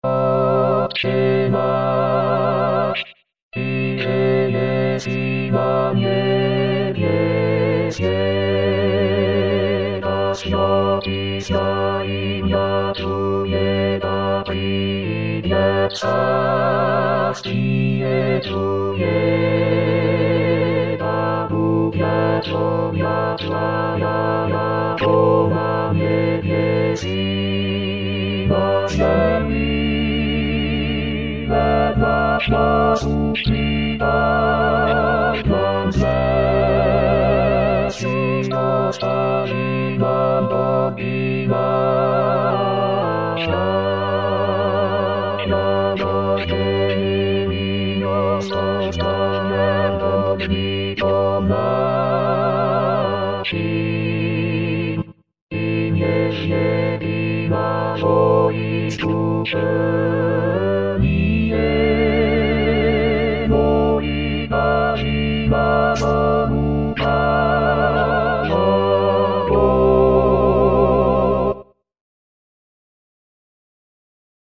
Tutti.mp3